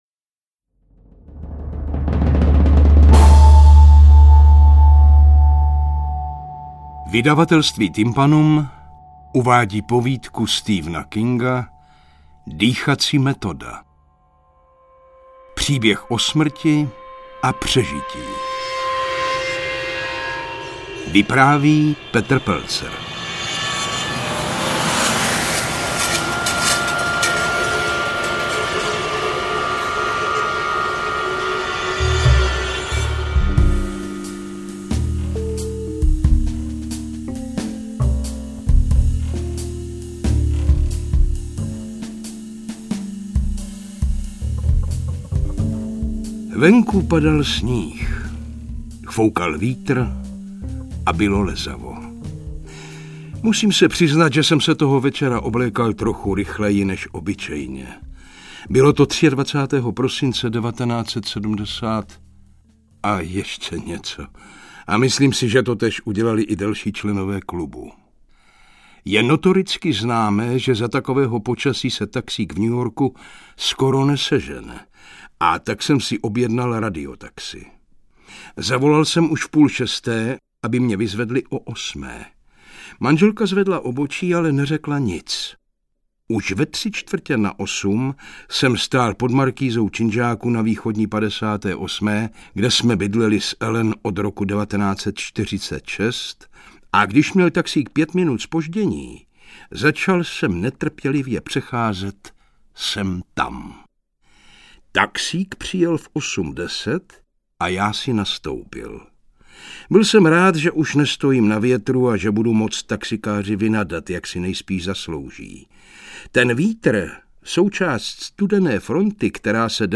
Interpret:  Petr Pelzer